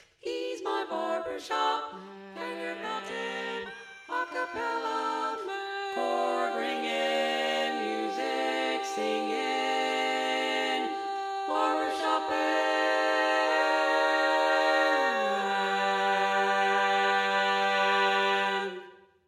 Key written in: G Major
Type: Female Barbershop (incl. SAI, HI, etc)